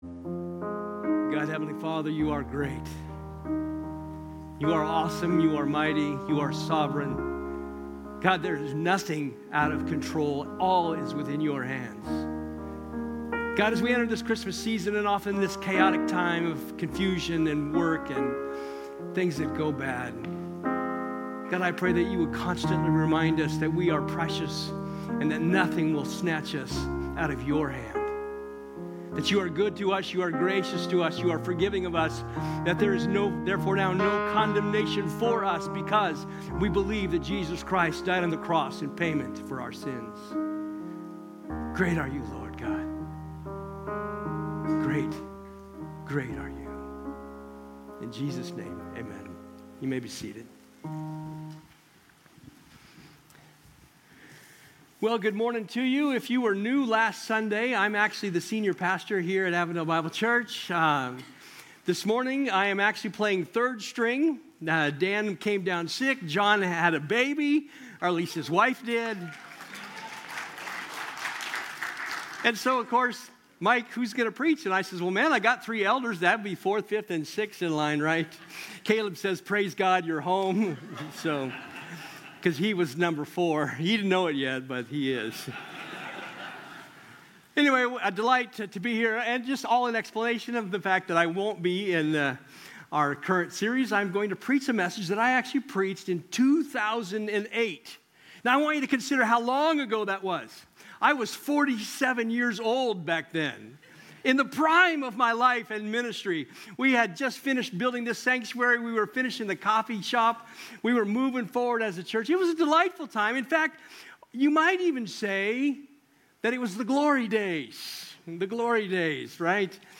From Series: "Topical Sermons"